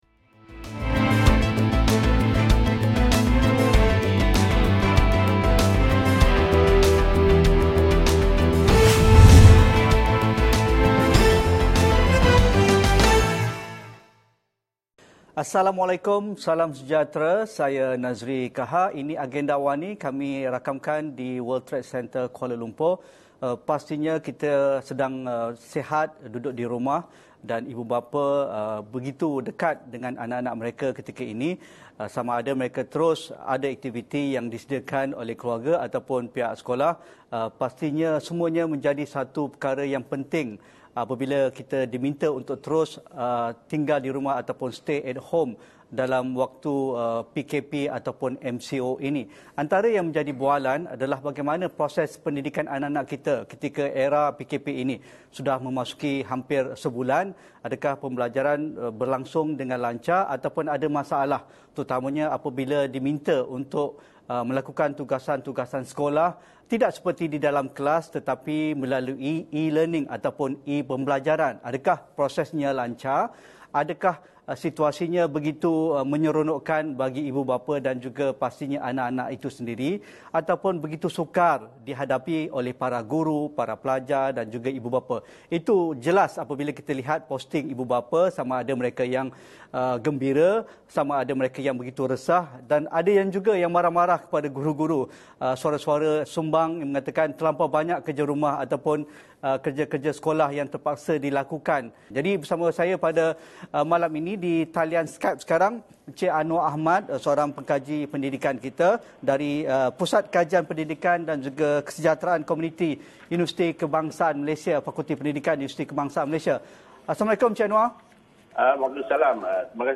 Headliner Embed Embed code See more options Share Facebook X Subscribe Pembelajaran di rumah sepanjang tempoh PKP, apa usaha serta cadangan penambahbaikan bagi memastikan tiada murid yang ketinggalan? Temu bual